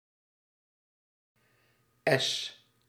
Ääntäminen
US : IPA : [ˈæʃ]